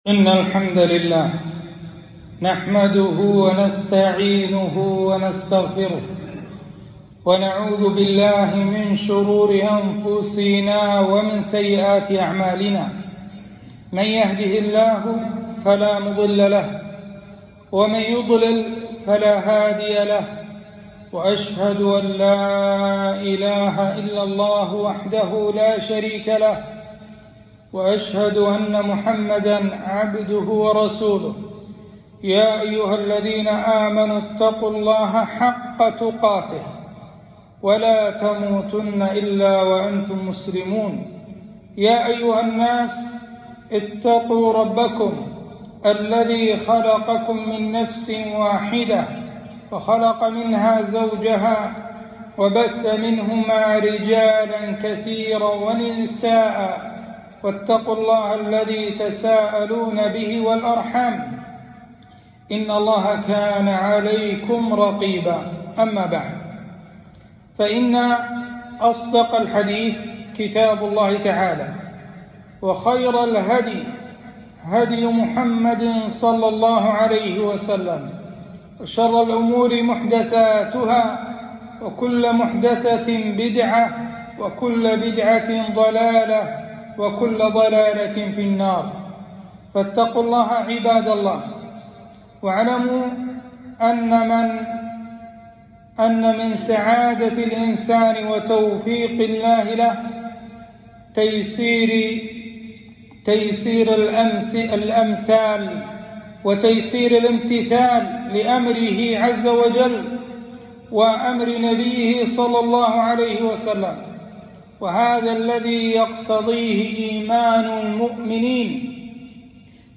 خطبة - وبالوالدين إحسانا